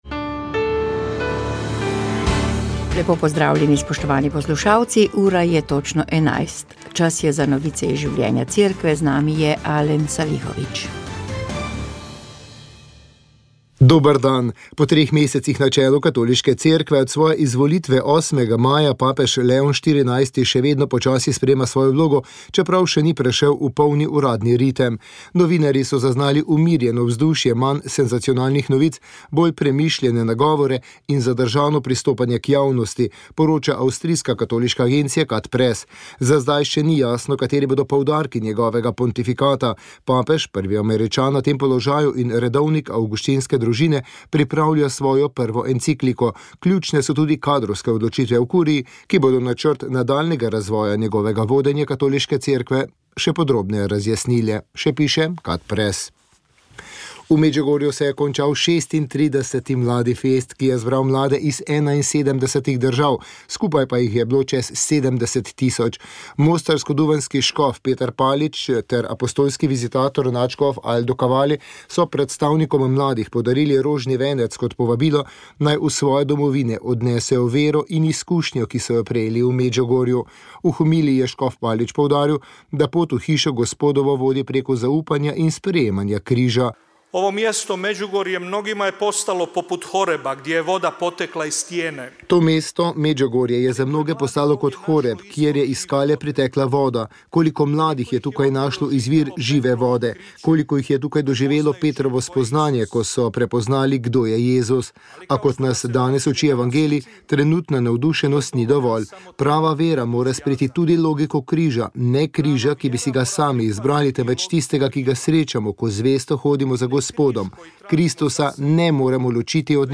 Radio Ognjišče info novice Informativne oddaje VEČ ...